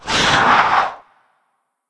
c_slith_atk2.wav